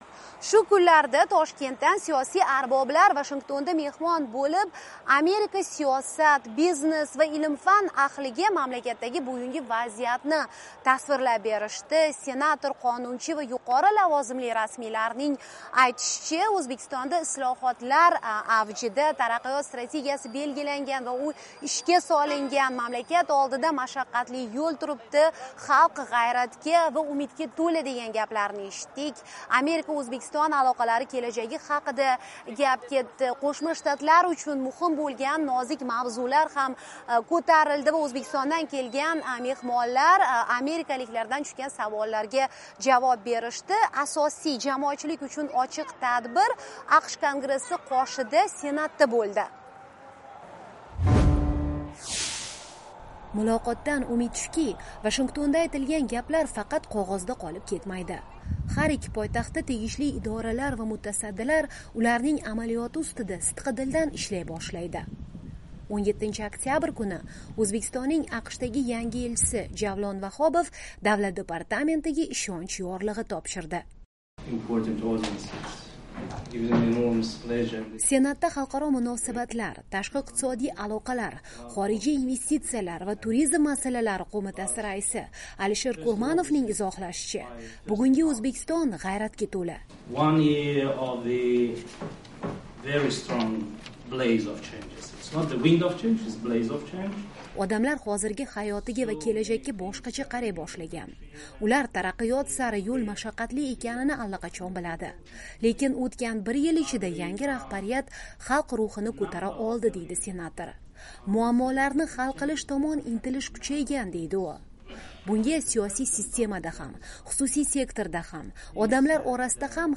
Oliy Majlis a'zosi Akmal Burhanov bilan suhbat, Vashington